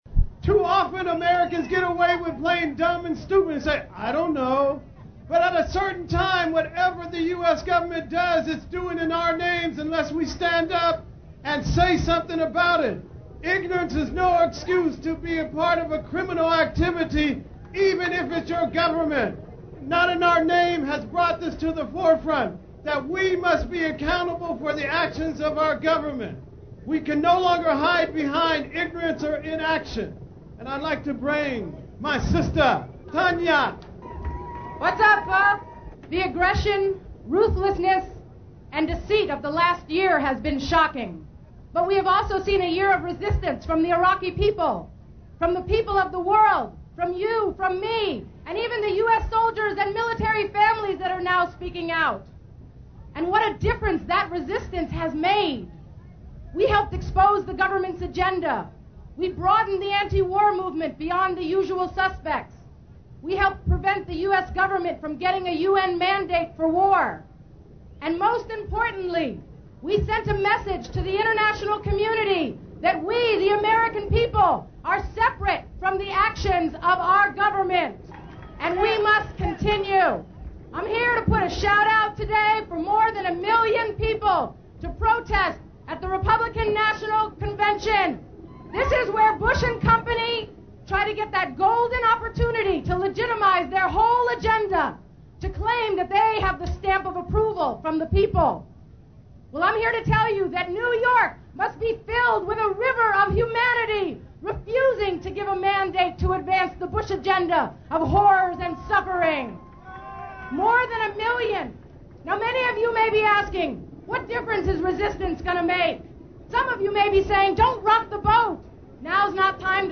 Audio from the March 20, 2004 "Global Day of Action" against occupation, San Francisco.
actor Woody Harrelson address the rally (6:45 min)